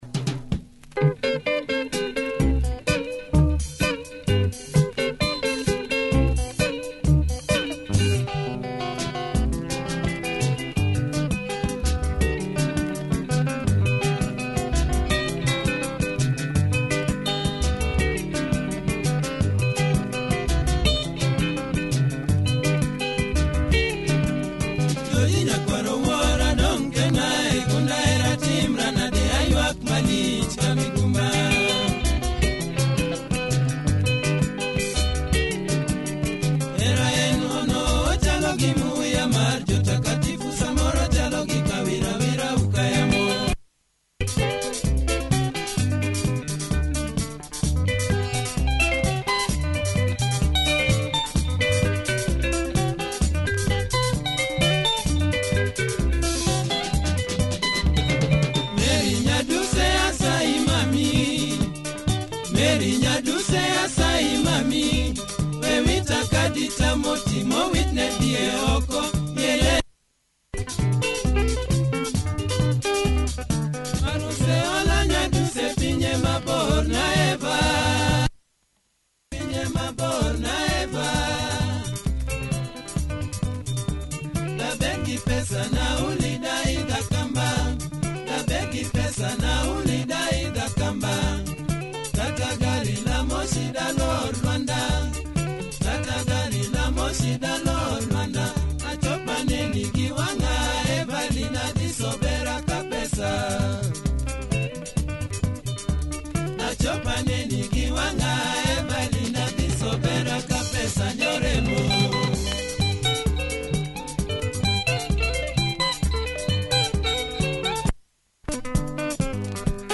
Pumping luo benga, check audio of both sides! https